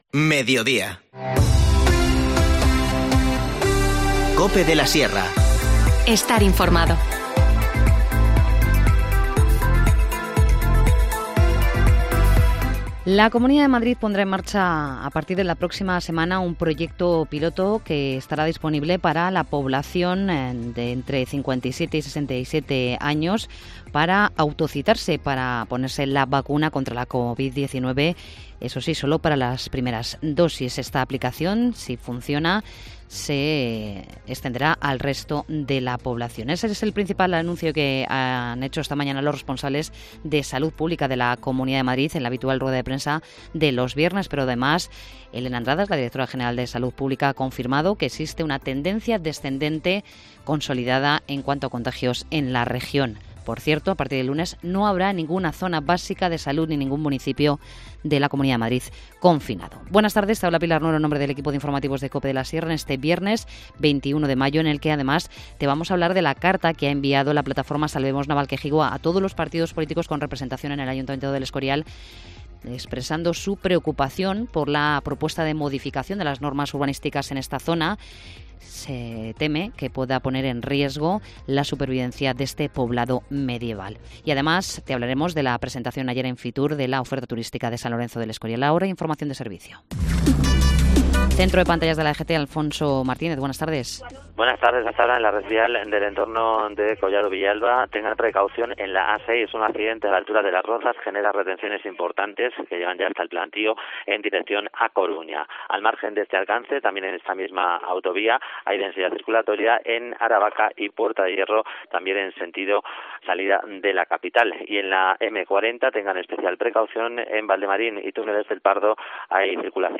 Informativo Mediodía 21 mayo